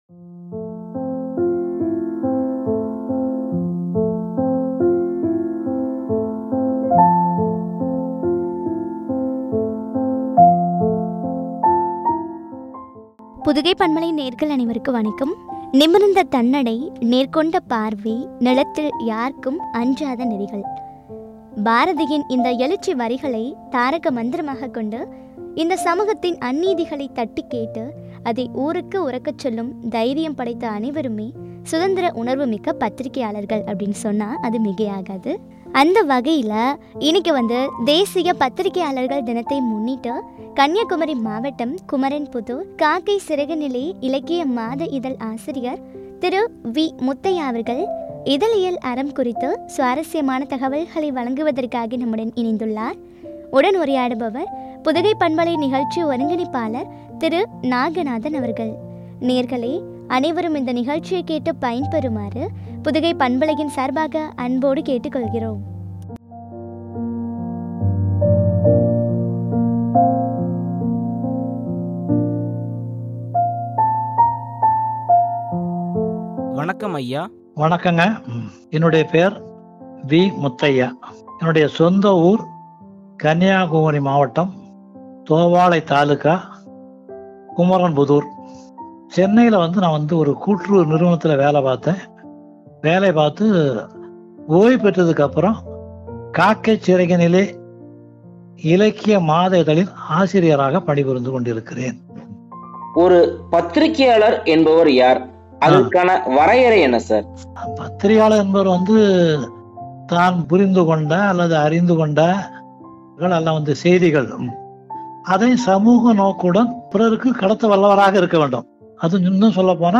” இதழியல் அறம்” குறித்து வழங்கிய உரையாடல்.